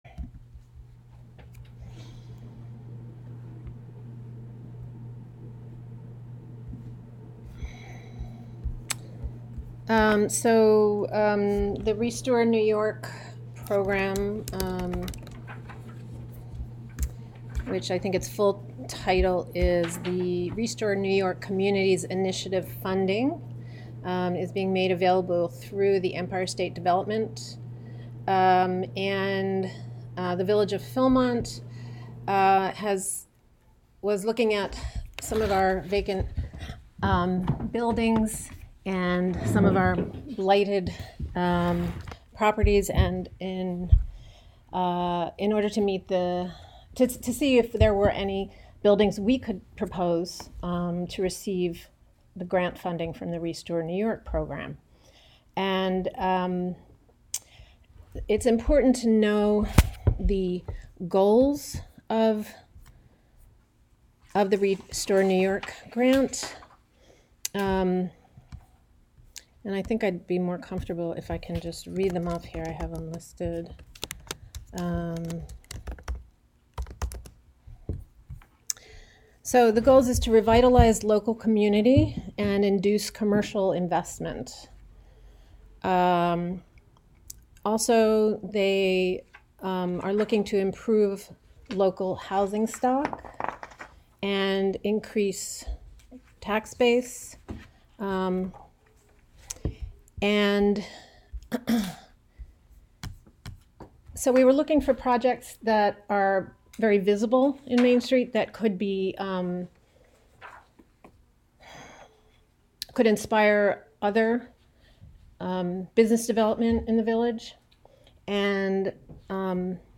Live from the Village of Philmont: Special Meeting (Audio)